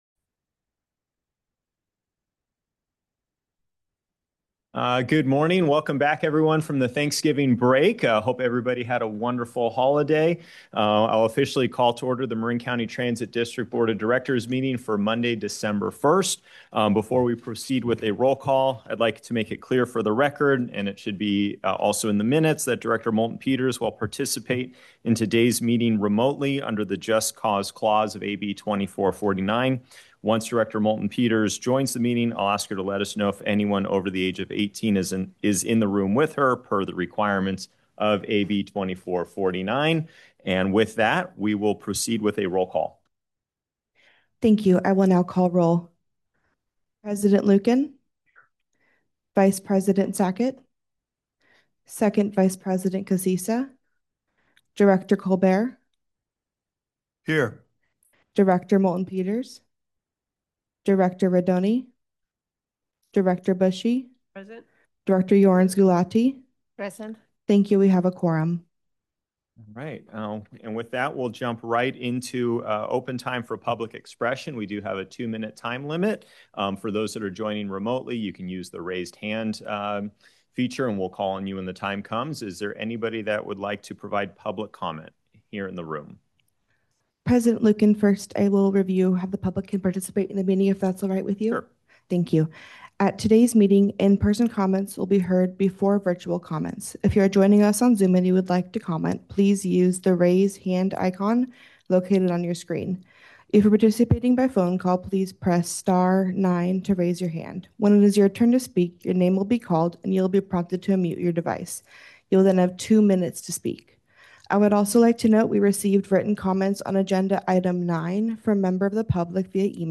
December 2025 Board of Directors Meeting
Members of the public can provide comment during open time and on each agenda item when the Board President calls for public comment. In-person comments will be heard before virtual comments.